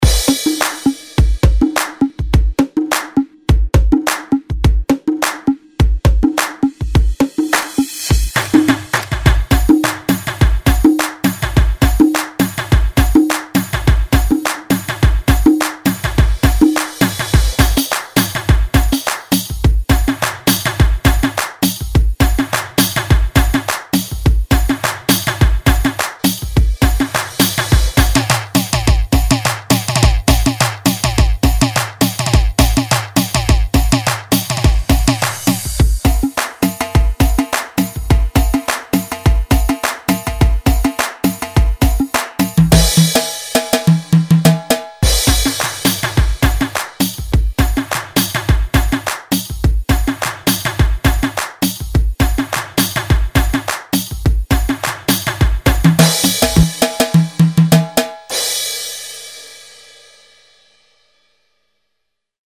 [ 112 BPM ]